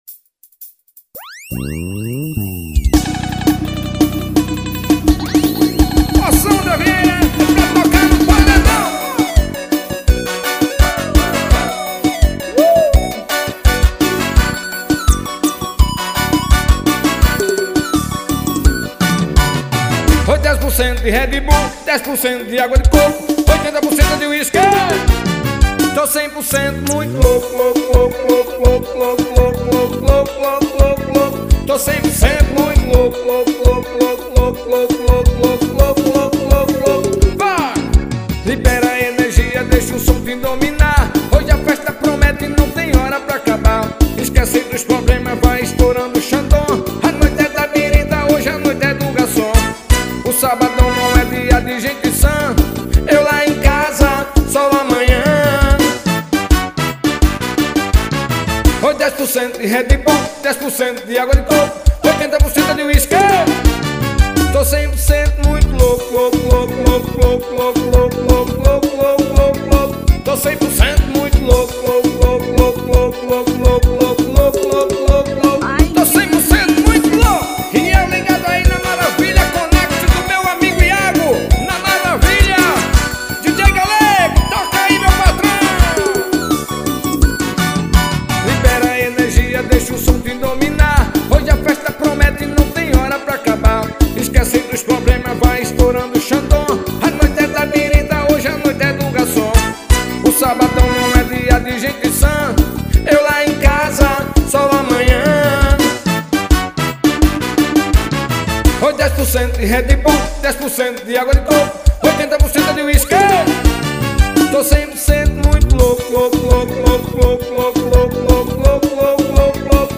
Forró Pegado.